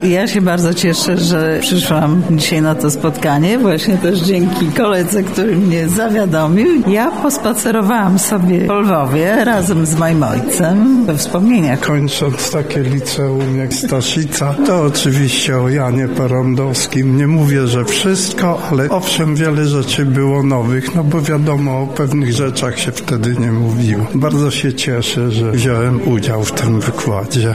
Słuchacze wykładu zanurzyli się także we własne wspomnienia.